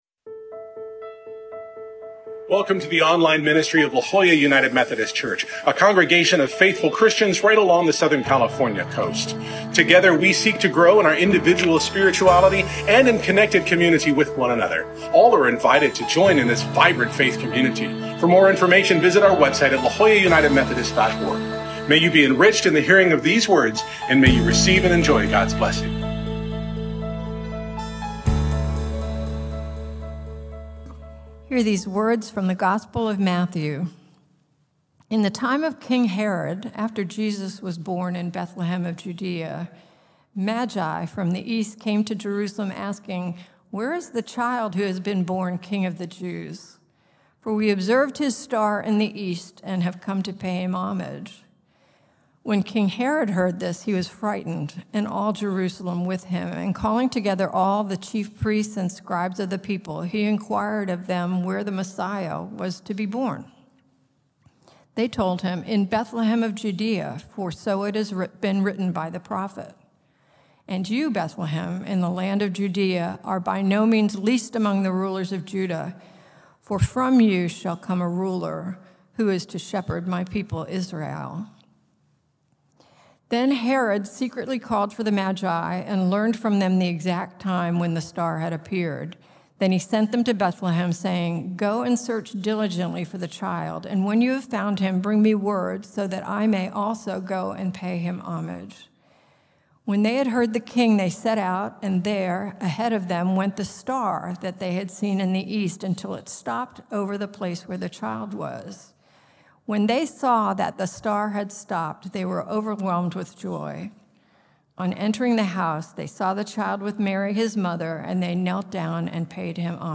In this final sermon of our Advent series based on Adam Hamilton’s The Journey, we follow the Magi as they travel over 1,350 miles to find a king—only to arrive at the wrong destination.